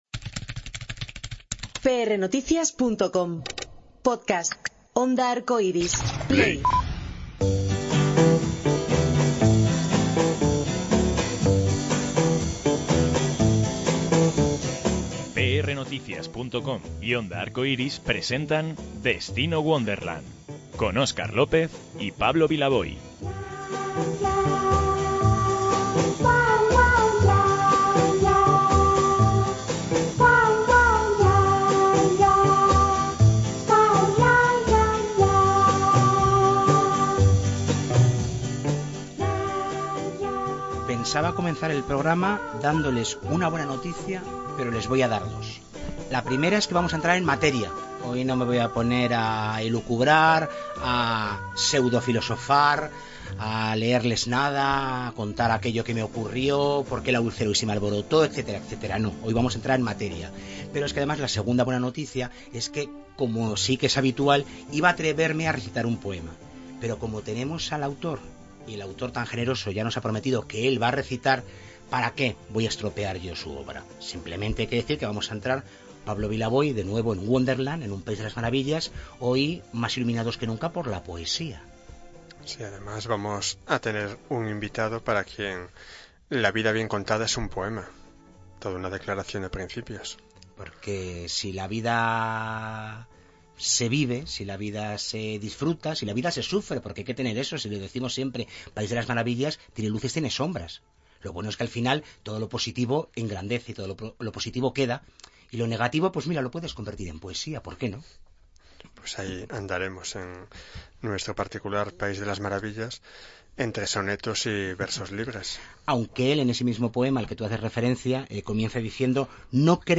Destino: Wonderland” para hablar de esta obra y se ofreció gustoso a recitar algunos poemas y regalar a los oyentes aquello que le ha hecho popular y acreedor de seguidores muy fieles: su voz.